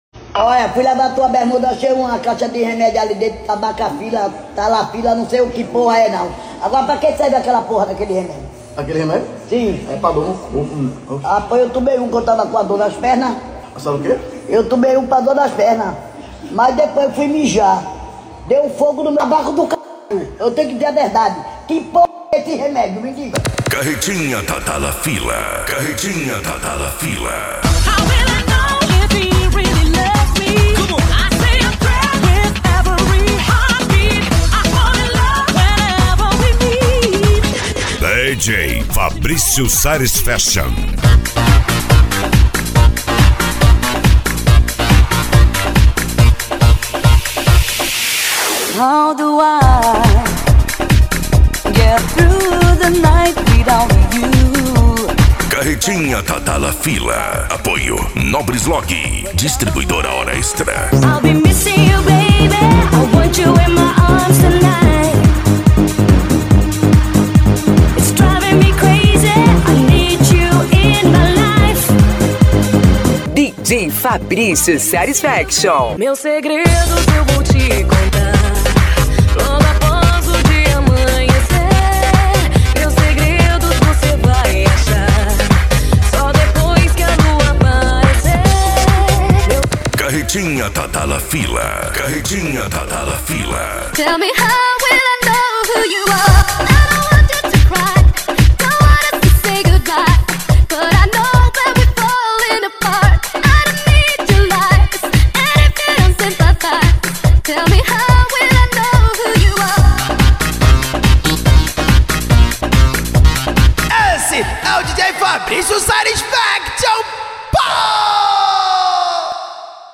PANCADÃO
Retro Music
SERTANEJO